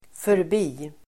Uttal: [förb'i:]